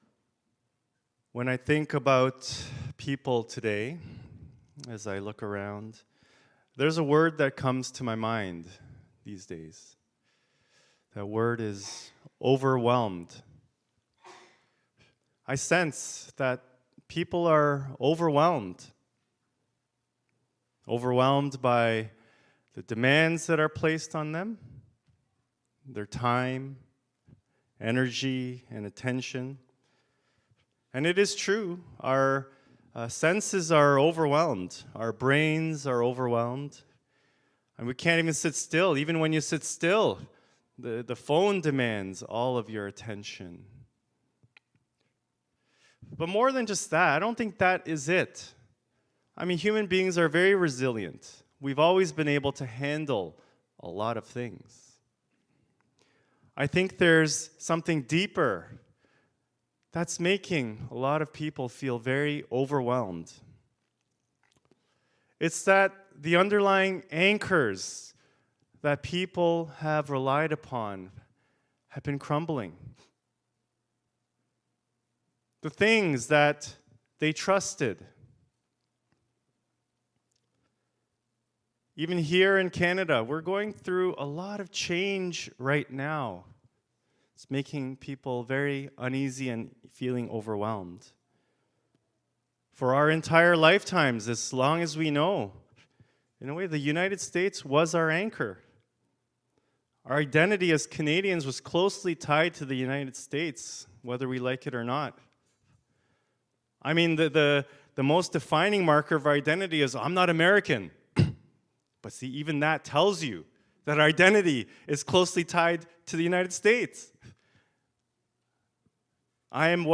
Scripture Passage Deuteronomy 26:1-11 Worship Video Worship Audio Sermon Script When I think about people today, there’s a word that comes to my mind: overwhelmed.